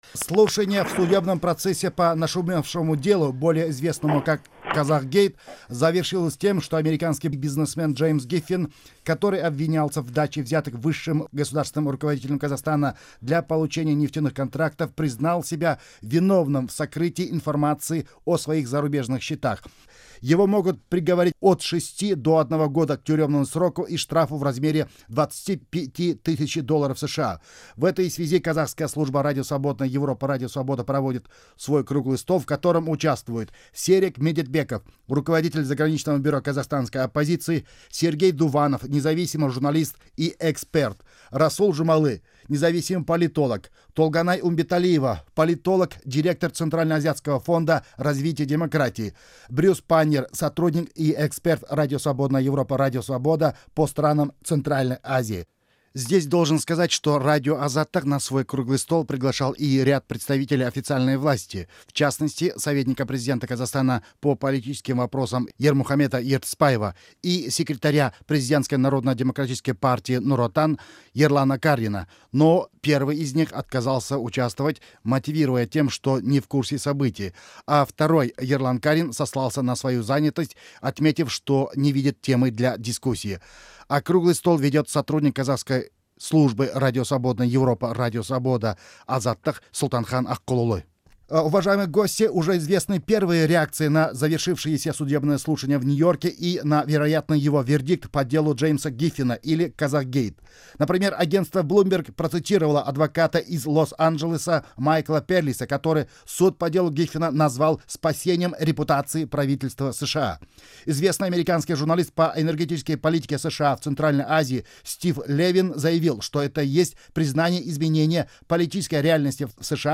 Аудиозапись Круглого стола по "Казахгейту"